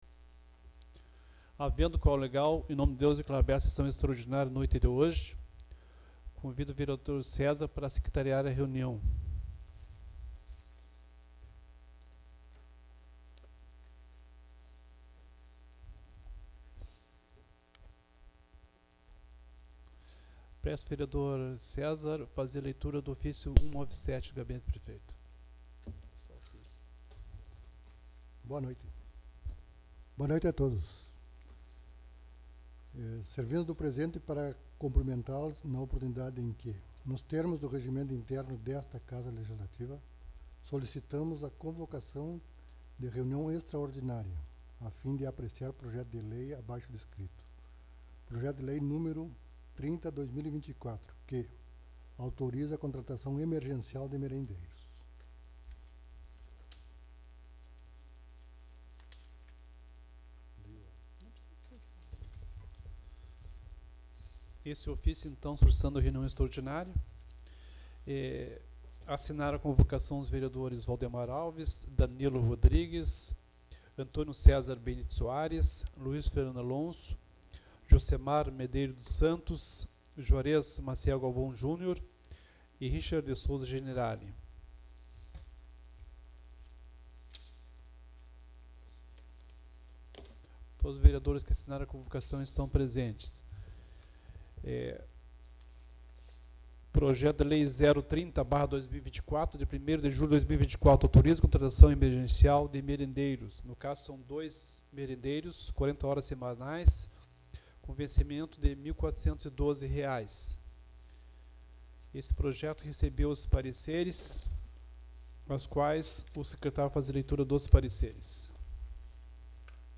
Sessão Extraordinária 03/07/2024 — Câmara Municipal de Barra do Quaraí-RS
Sessão Extraordinária 03/07/2024